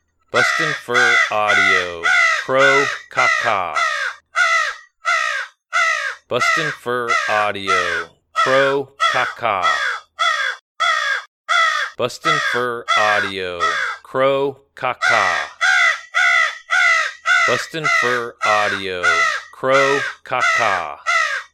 Multiple crows that are worked up over an animal distress sound. Good sound to play when calling predators in the daytime to add realism to your set.
BFA Crow Ka Ka Sample.mp3